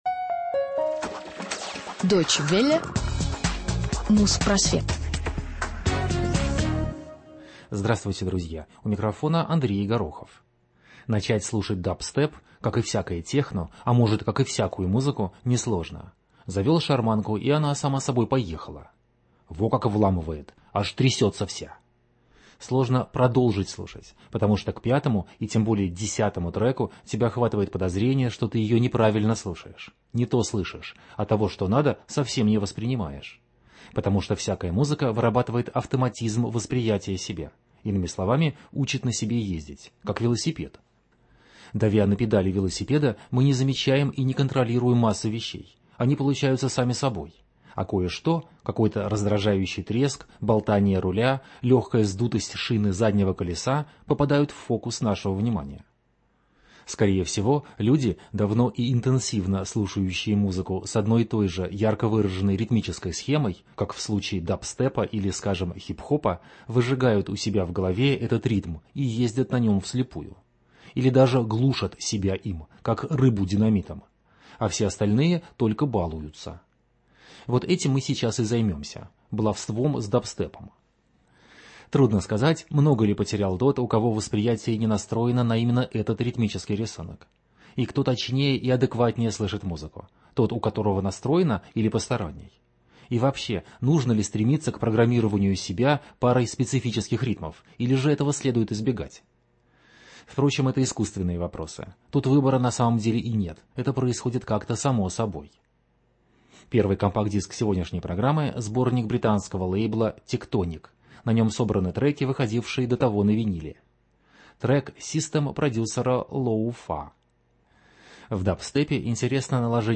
Музпросвет 313 от 9 августа июля 2008 года б Баловство с дабстепом | Радиоархив